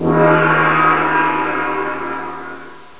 gong.wav